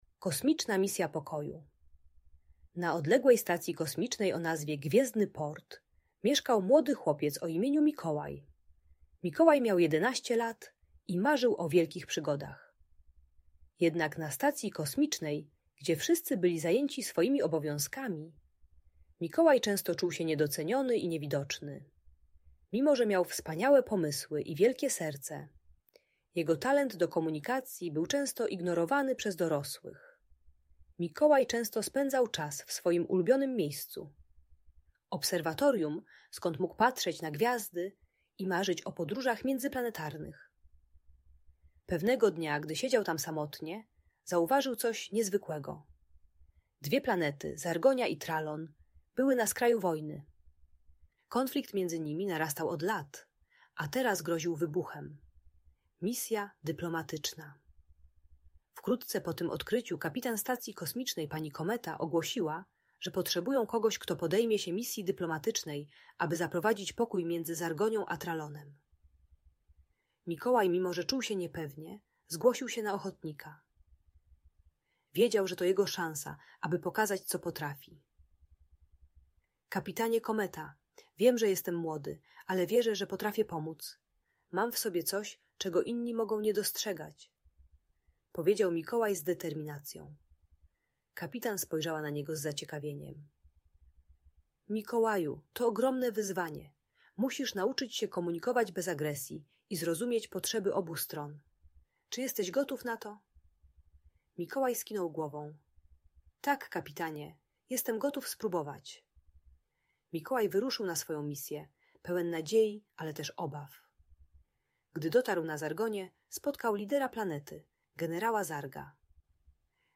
Kosmiczna Misja Pokoju - Audiobajka